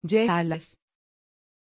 Aussprache von Charles
Türkisch
pronunciation-charles-tr.mp3